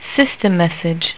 Questa e' la finestra principale di ICQ...appena installato...e senza nessuno nella lista...lampeggia il quadratino giallo accanto alla scritta "System"...ti indica che hai ricevuto un messaggio di "sistema" ed e' accompagnato da questo
system.wav